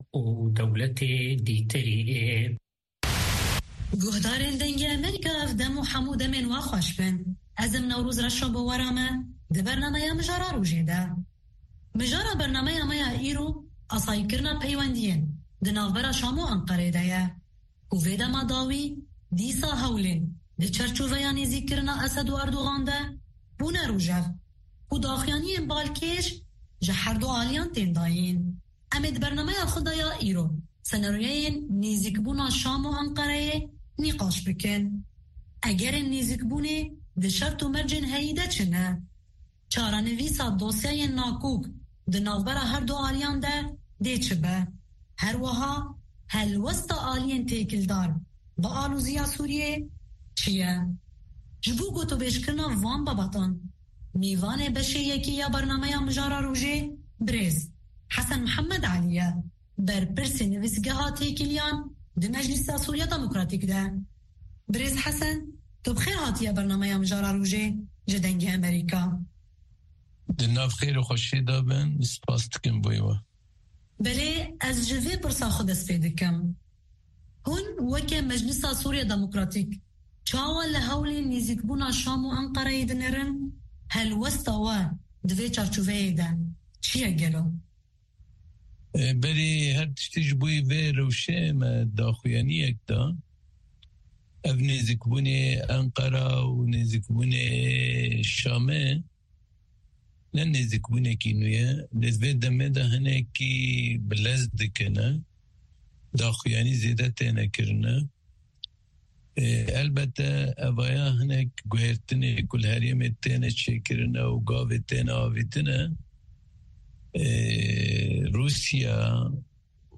هەواڵە جیهانیـیەکان لە دەنگی ئەمەریکا